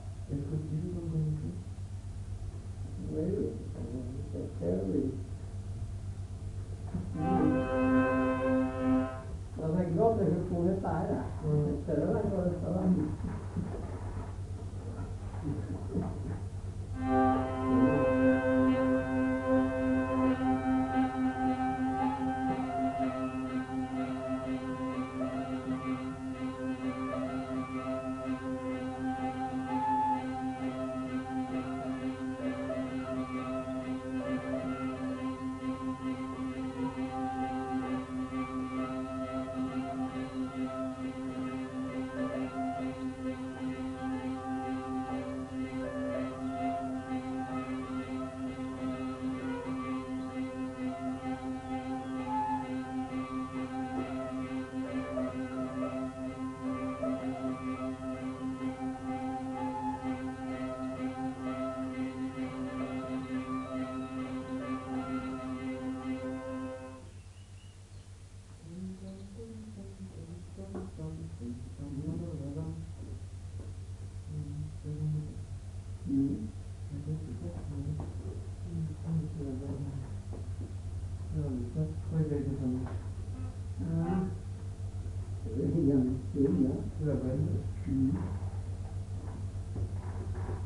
Aire culturelle : Petites-Landes
Lieu : Lencouacq
Genre : morceau instrumental
Instrument de musique : vielle à roue
Danse : mazurka